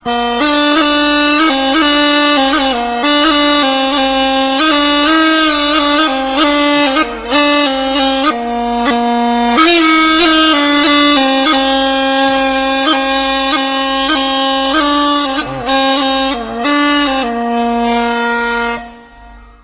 AULOS
03_Aulos.ra